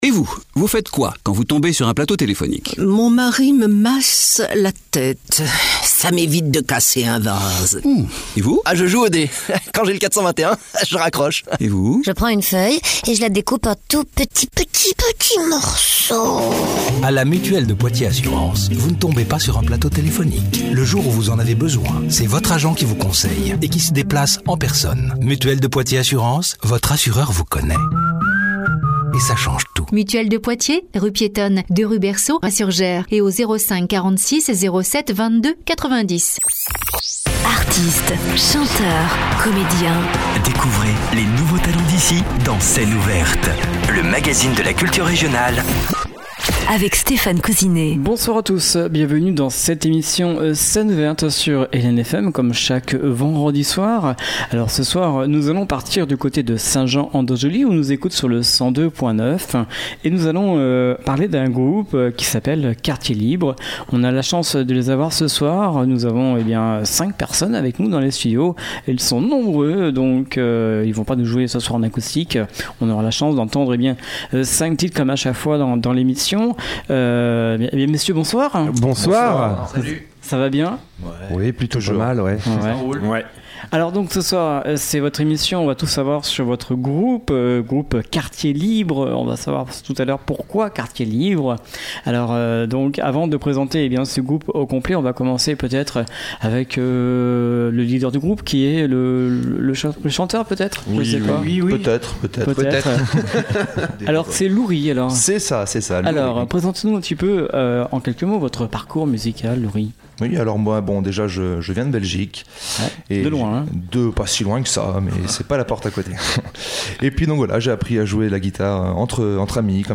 batterie Formation rock.